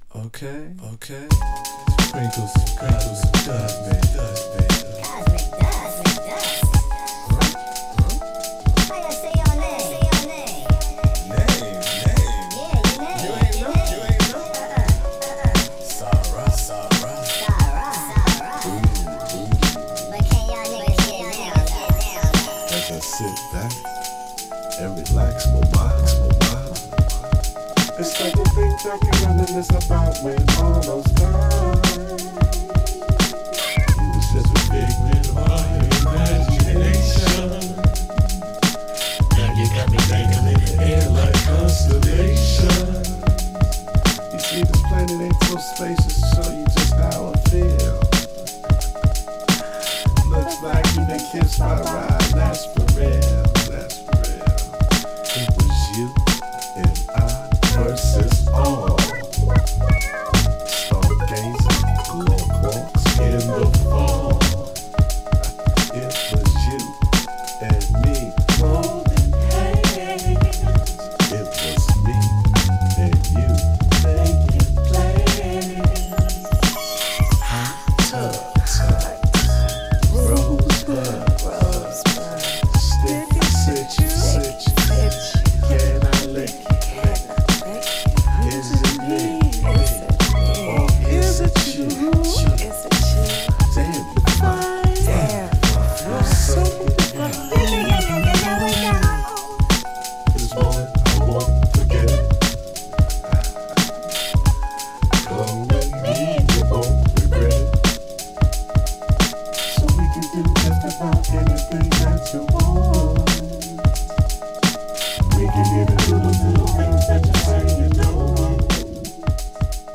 次世代のコズミックファンクに満ちた絶品アルバム！